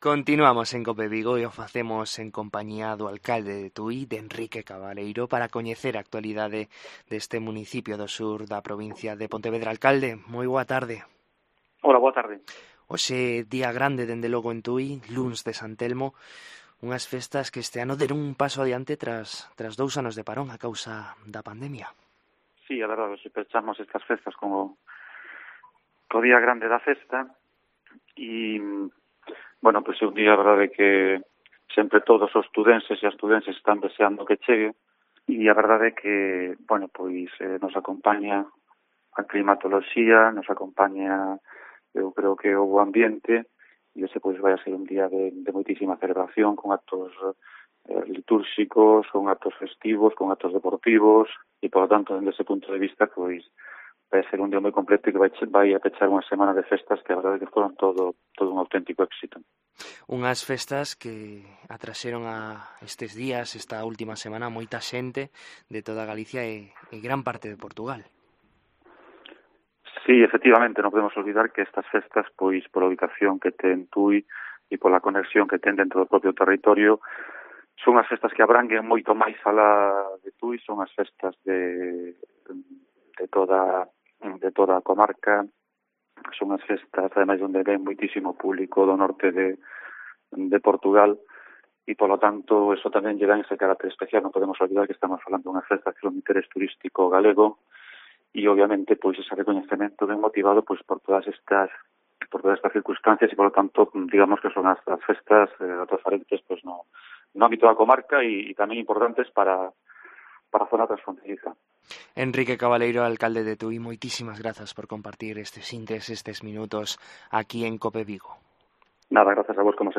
Entrevista a Enrique Cabaleiro, alcalde de Tui: "Pechamos as festas de San Telmo ao grande"